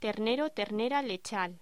Locución: Ternero/ternera lechal
voz